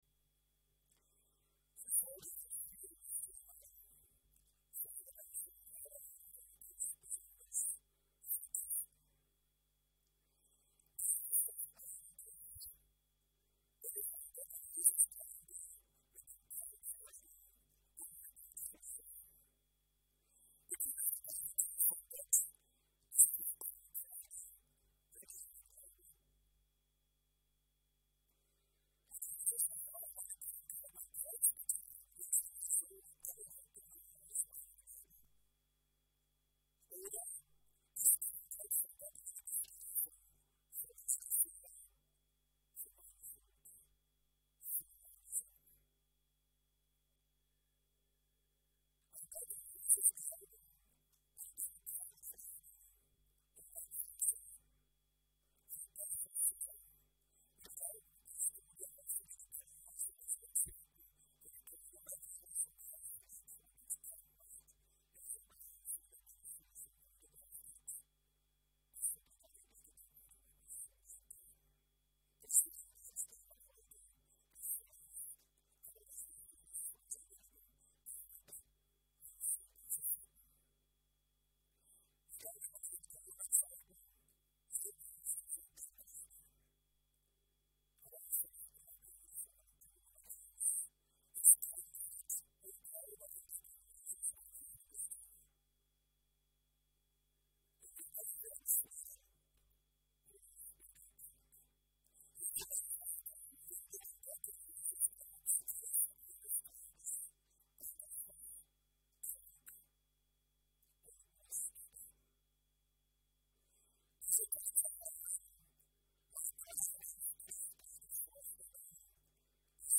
Hinweis: Aufgrund eines technischen Fehlers während der Aufzeichnung ist der Ton auf manchen (mobilen) Endgeräten nicht hörbar.
Dienstart: Gottesdienst